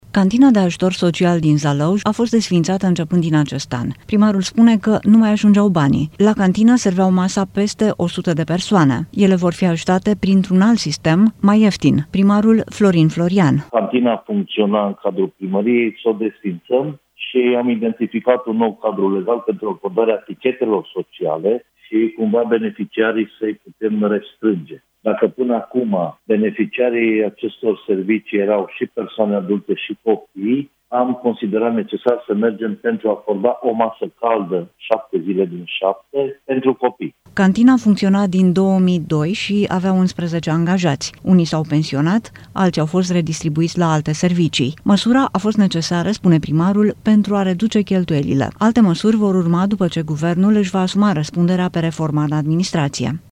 Primarul orașului Zalău, Florin Florian: „Am considerat că este necesar să oferim o masă caldă șapte zile din șapte doar pentru copii”